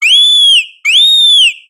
Cri de Plumeline Style Pom-Pom dans Pokémon Soleil et Lune.